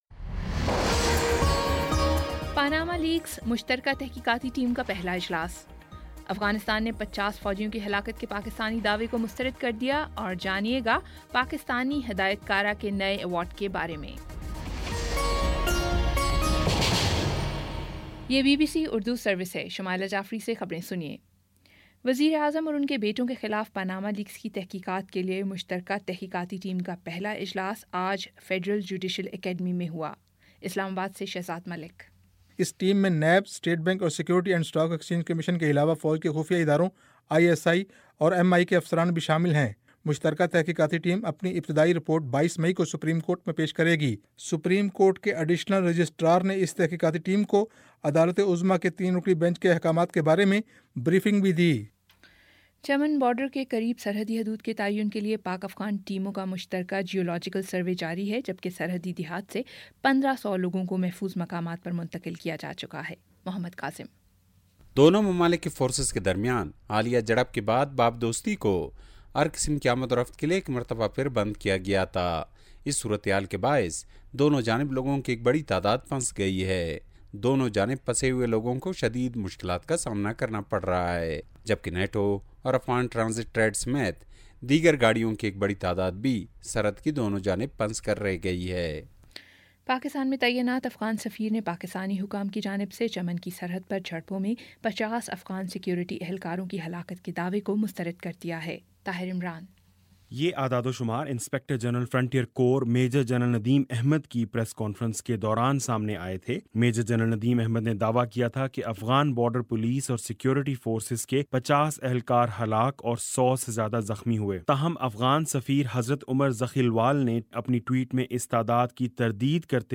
مئی 08 : شام چھ بجے کا نیوز بُلیٹن